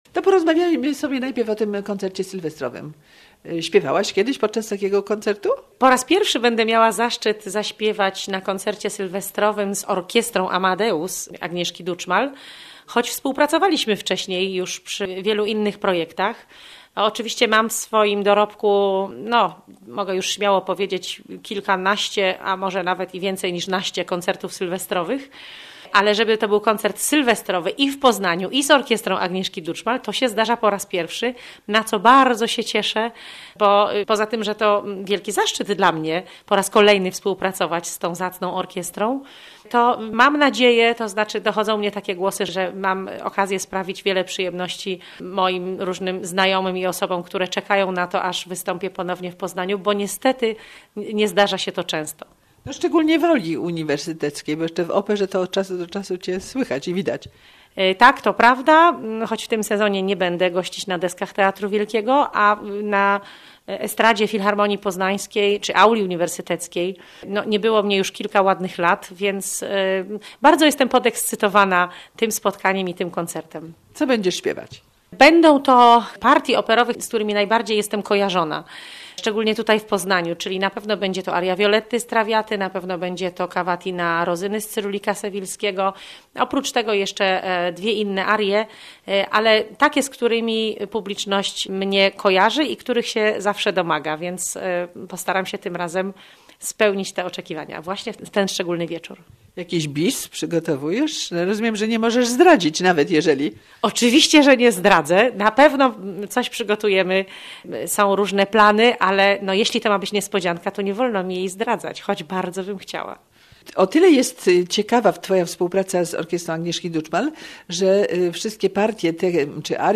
Podczas sylwestrowego wieczoru artyści zaprezentują najpiękniejsze arie operowe, porywające walce i fragmenty muzyki filmowej w najlepszym wydaniu. Na koncert zapraszają muzycy Orkiestry Kameralnej Polskiego Radia Amadeus.